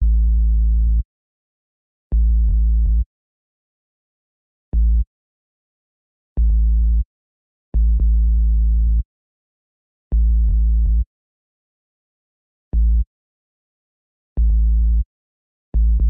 描述：使用GMS（默认VST）在FL中创建的一个小“wobwob”声音，如果你使用它，请给我留言，这样我就可以为自己感到自豪。
标签： 配音 摆动 分步骤 漂亮 dubstep的 效果 LFO 摆动
声道立体声